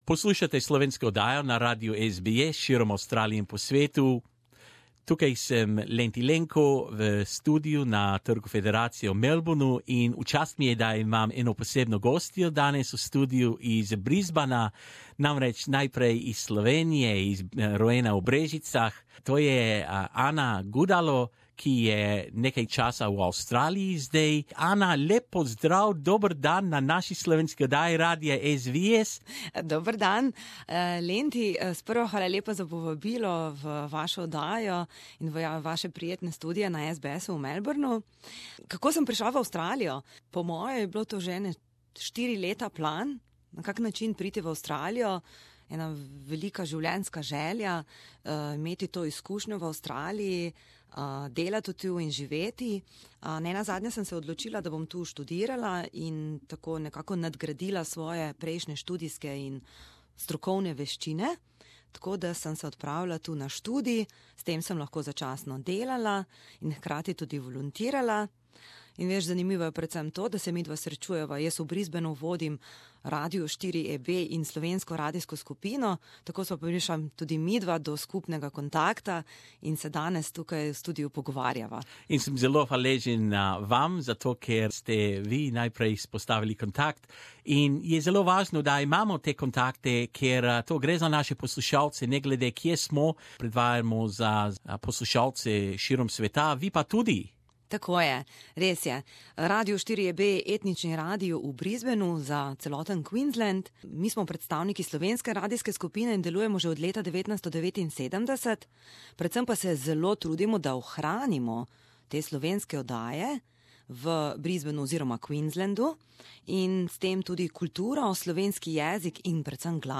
come live into the studio at Melbourne's Federation Square as our special guest